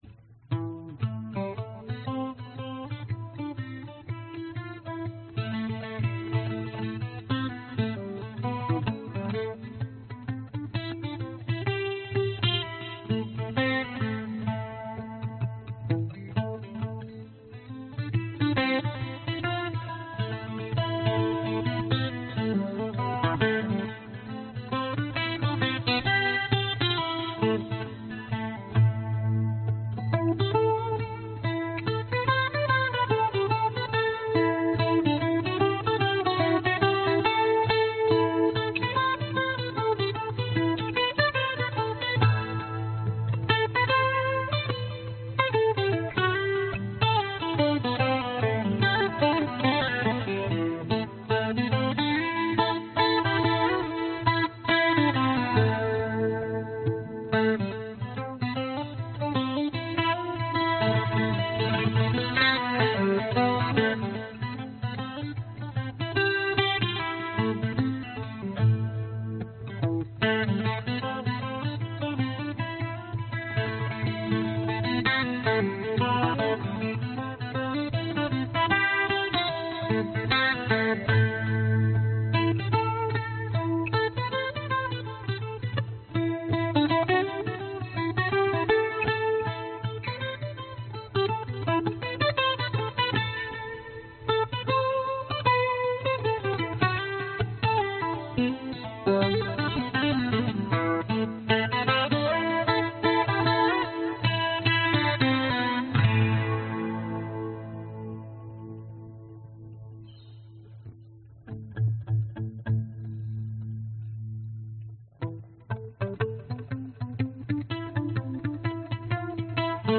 爱尔兰曲子，用原声吉他演奏。
Tag: 吉他 原声 传统 民俗 爱尔兰 凯尔特人 器乐 电影音乐 视频音乐